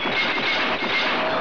File: "3 metal blows" (3 colpi metallici )
Type: Sound Effect